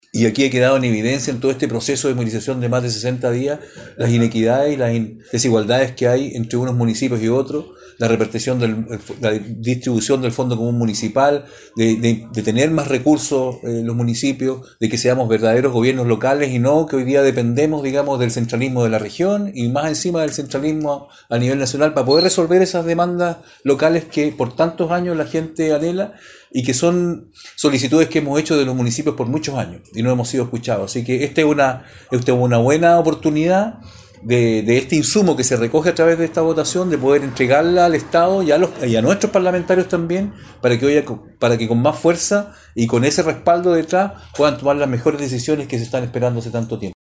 En conferencia de prensa los alcaldes de la provincia del Huasco, César Orellana de Freirina, Rodrigo Loyola de Huasco y Cristian Tapia Ramos de Vallenar, entregaron un balance del proceso democrático realizado en la provincia del Huasco durante la jornada de Consulta Ciudadana realizada en el país este domingo 15 de diciembre.